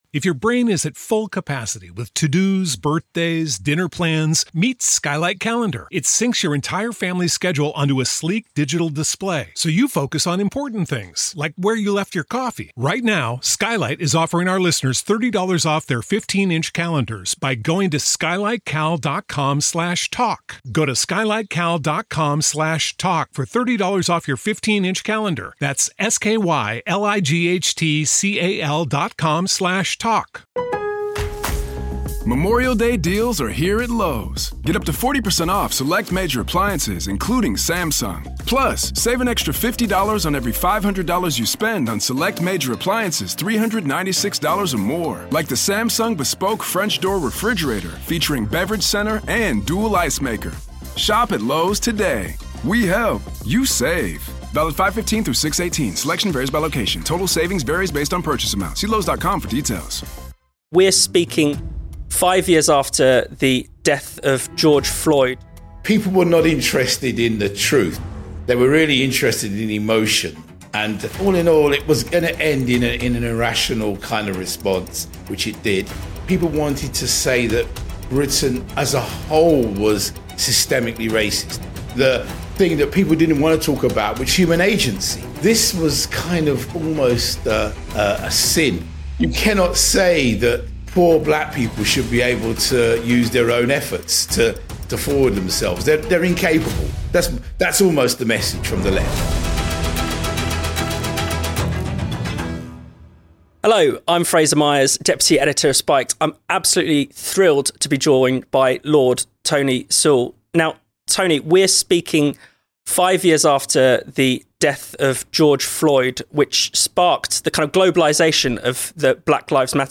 This is the audio from a video we have just published on our YouTube channel – an interview with Tony Sewell.